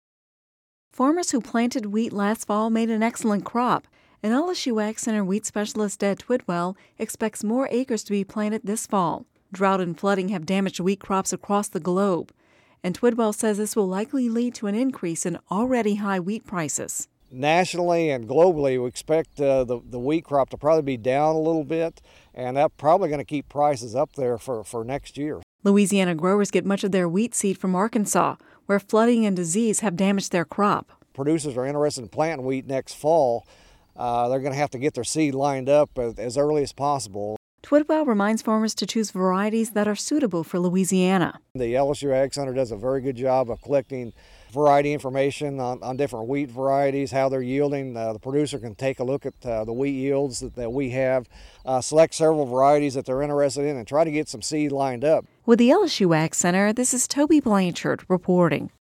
(Radio News 05/19/11) Louisiana farmers who planted wheat last year made an excellent crop